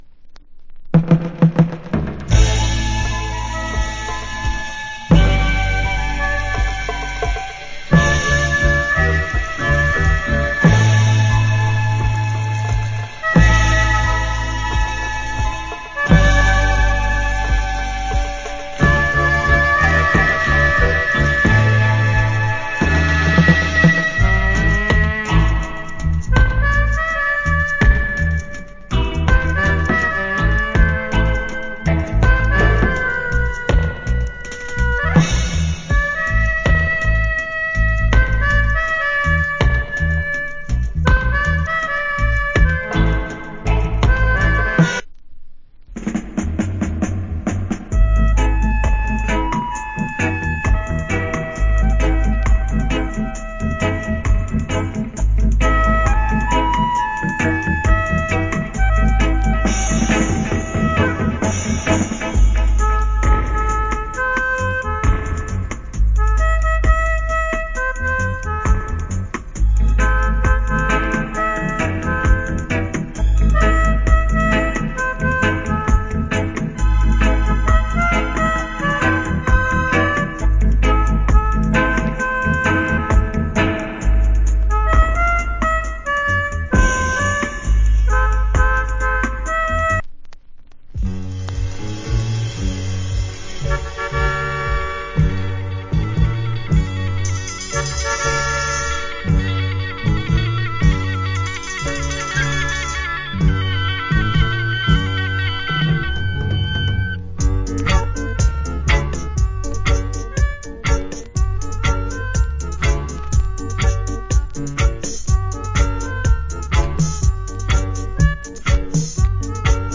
Nice Melidica Inst. 1992.